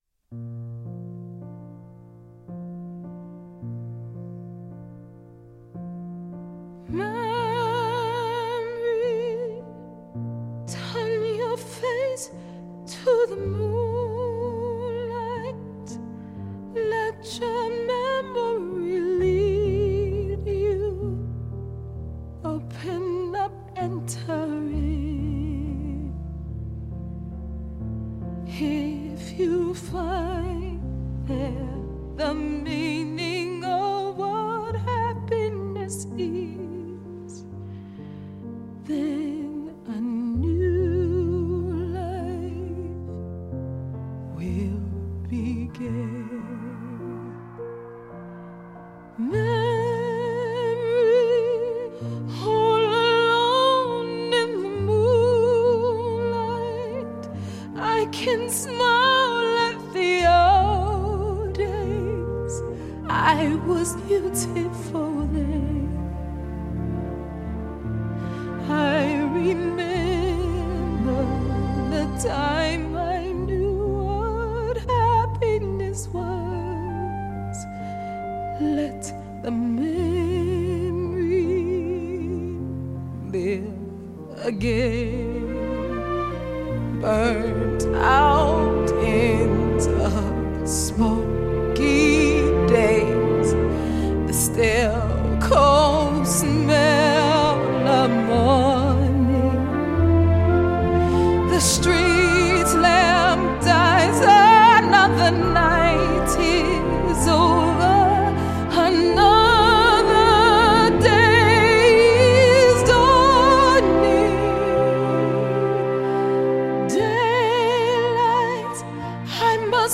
Жанр: Саундтреки / Саундтреки